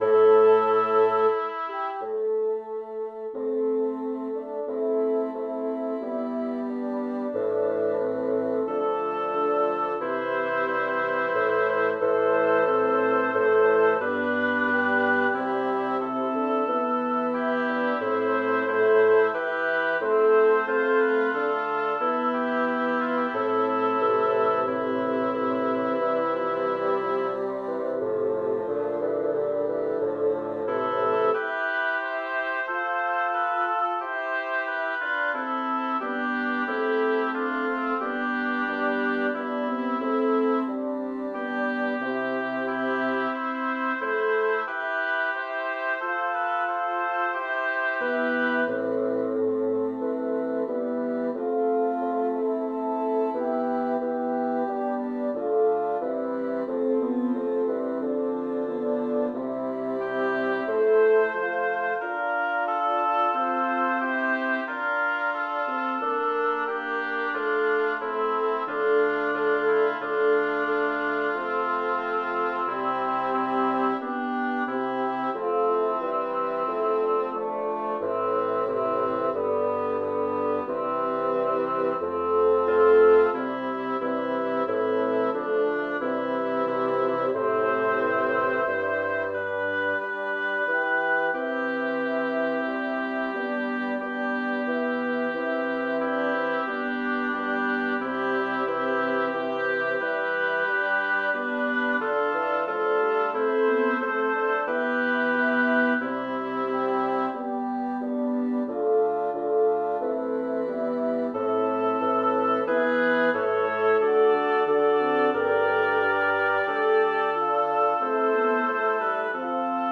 Title: Lasciato hai morte Composer: Giovan Nasco Lyricist: Francesco Petrarca Number of voices: 6vv Voicing: SSATTB Genre: Secular, Madrigal
Language: Italian Instruments: A cappella